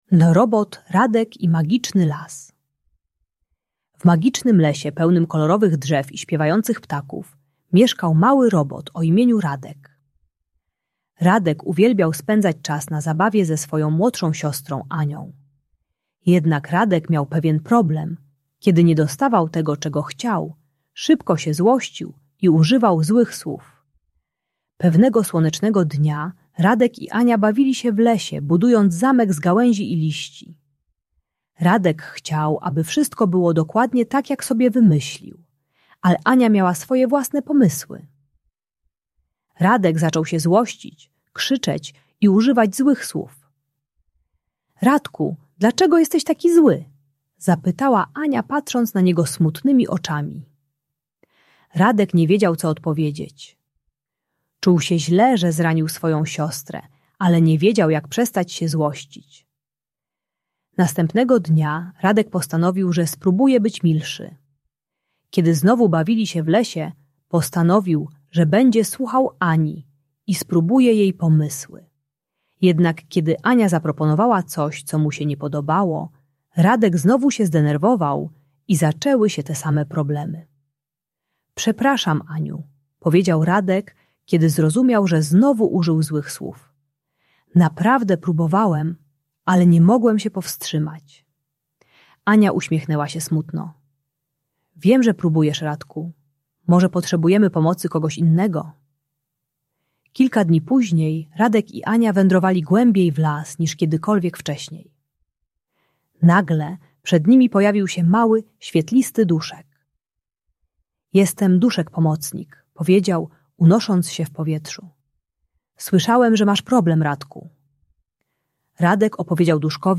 Uczy techniki liczenia do pięciu z głębokim oddechem oraz nazywania emocji i dzielenia się uczuciami z bliskimi. Audiobajka o radzeniu sobie ze złością dla dzieci.